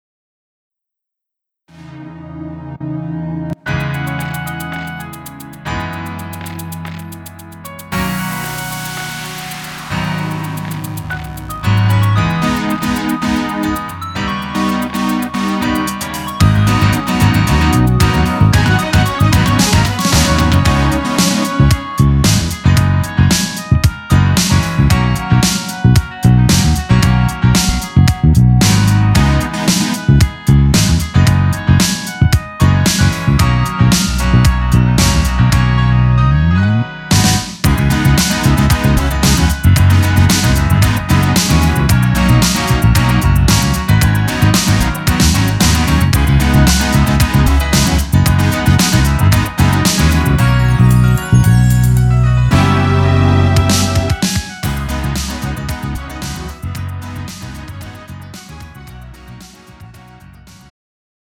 음정 남자키 3:14
장르 가요 구분 Pro MR
Pro MR은 공연, 축가, 전문 커버 등에 적합한 고음질 반주입니다.